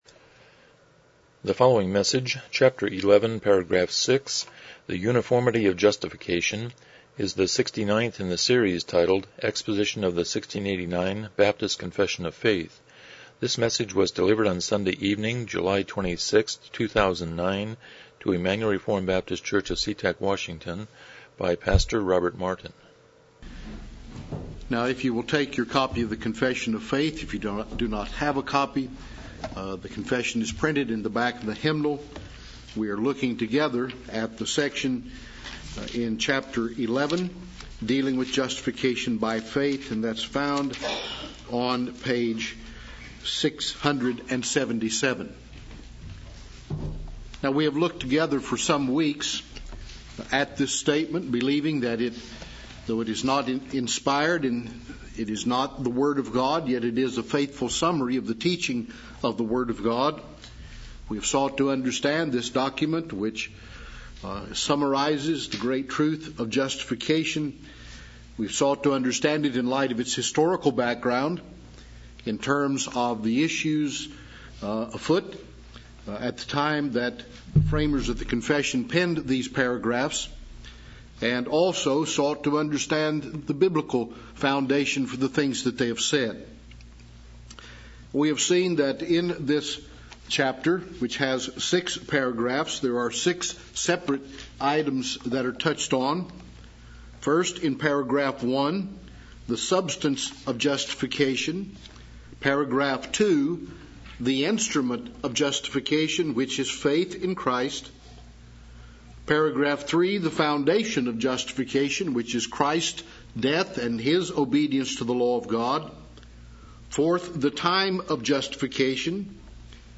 1689 Confession of Faith Service Type: Evening Worship « 85 Romans 7:15-17 41 The Abrahamic Covenant